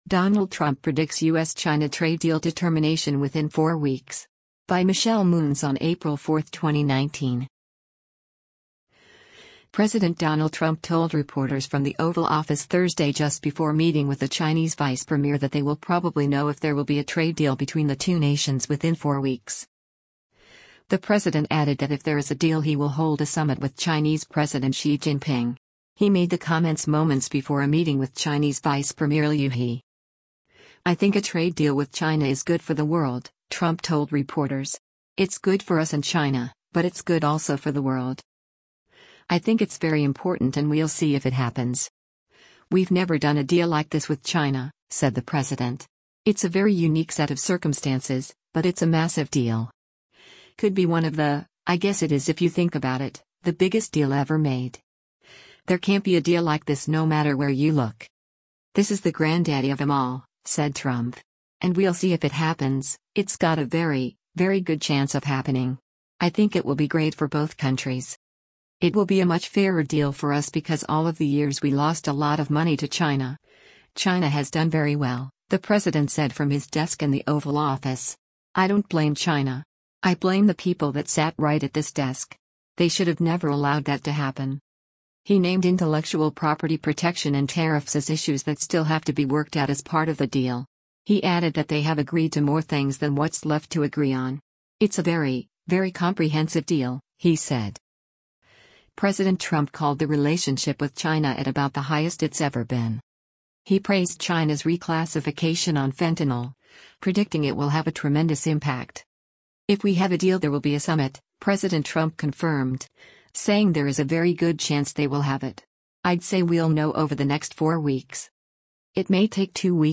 President Donald Trump told reporters from the Oval Office Thursday just before meeting with the Chinese Vice Premier that they will probably know if there will be a trade deal between the two nations within four weeks.